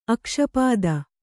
♪ akṣapāda